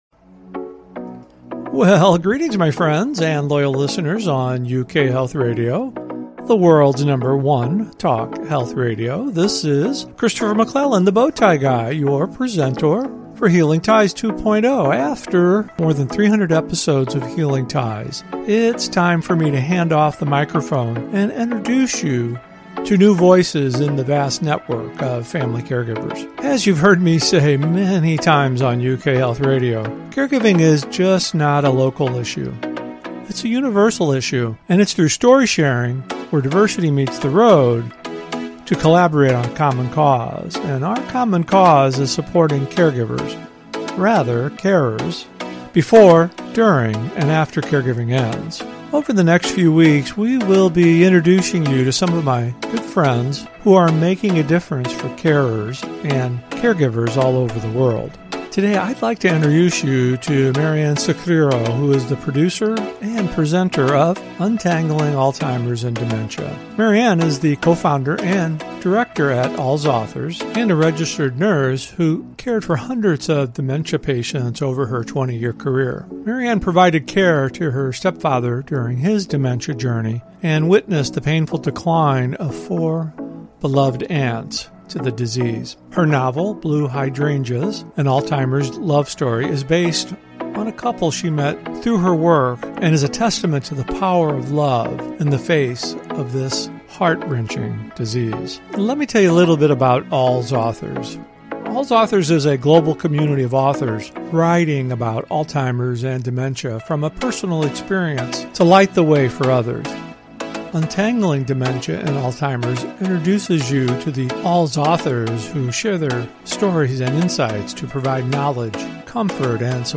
In each episode, we interview one of our authors who may have written a memoir, caregiver guide, novel, children’s book, activity guide, poetry book, or blog.